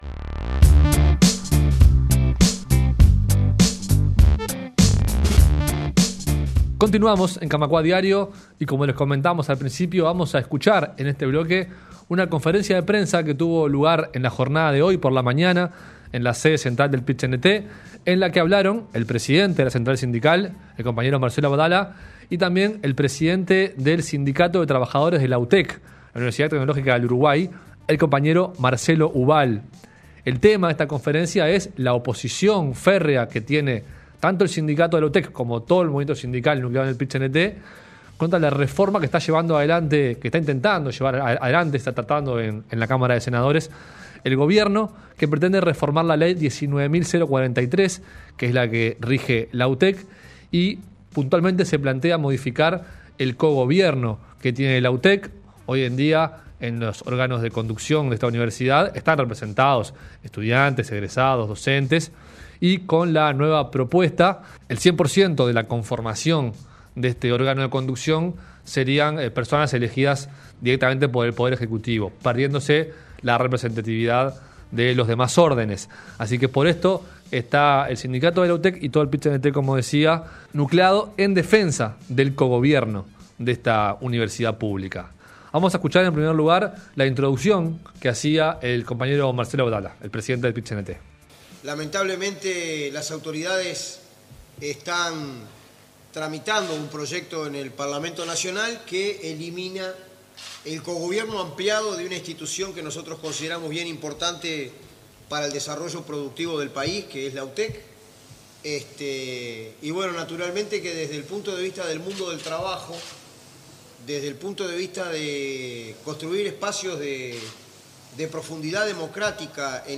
El Secretariado Ejecutivo del PIT-CNT hizo público, mediante una conferencia de prensa, su apoyo a la lucha de los compañeros del Situtec (Sindicato de Trabajadores de la Universidad Tecnológica) que defienden el cogobierno de la UTEC.